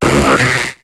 Cri de Serpang dans Pokémon HOME.